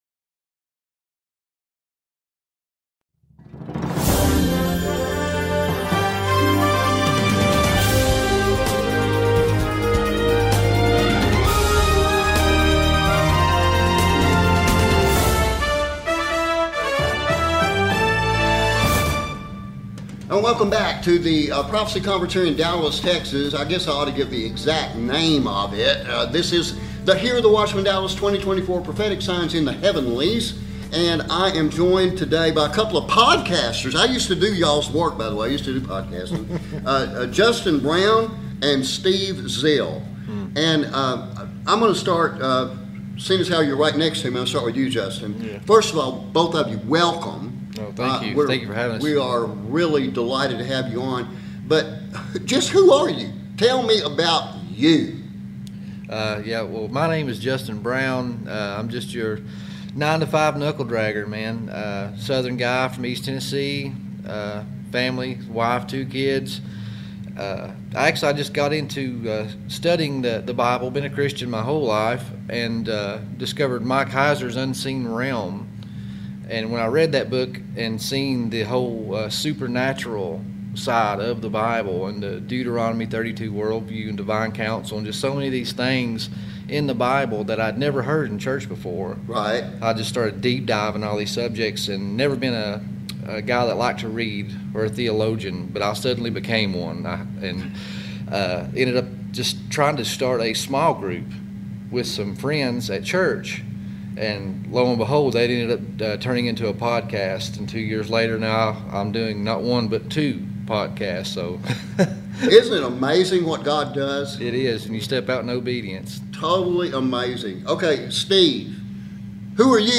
We do apologize for the Sound issues beyond our control caused by fans operating inside our Interview Room.